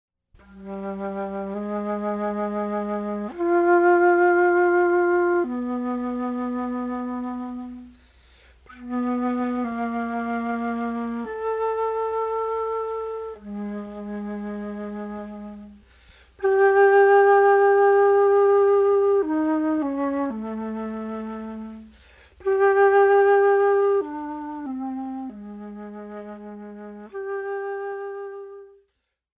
a truly eerie score